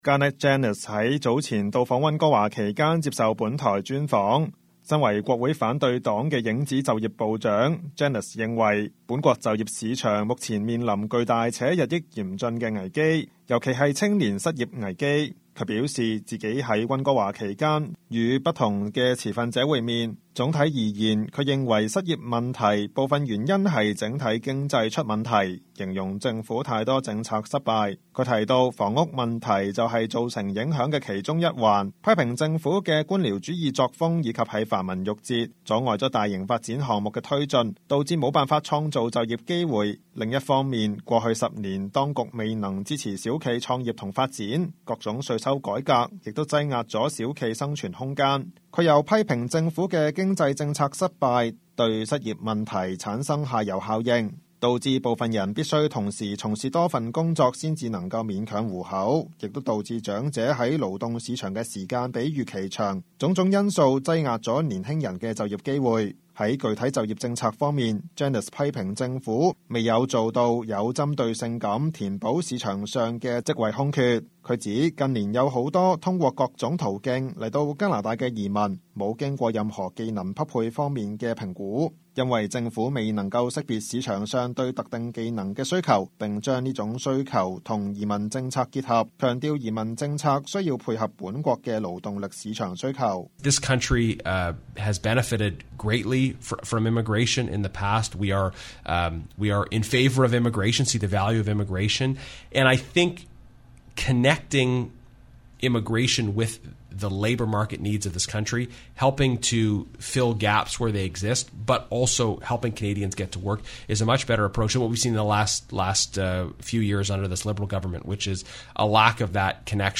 Garnett Genuis在早前到訪溫哥華期間，接受本台專訪。